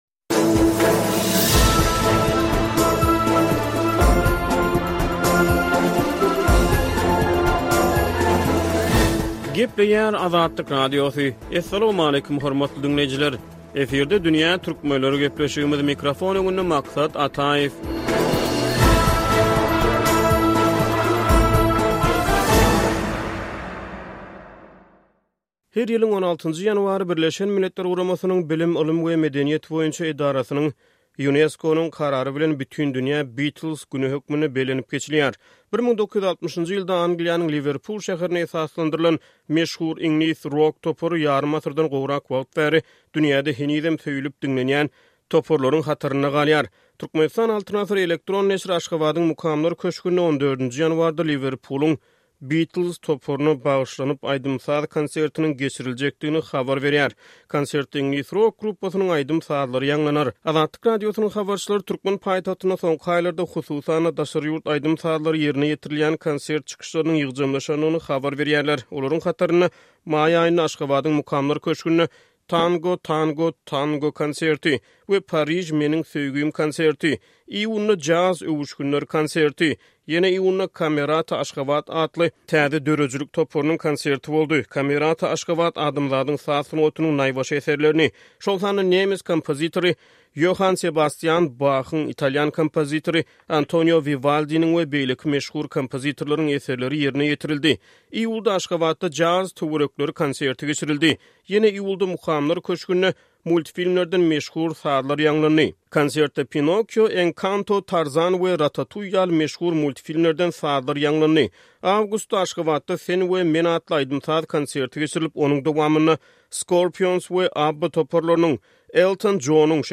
Dünýä Türkmenleriniň bu sany sungat söhbetdeşligine gönükdirilýär.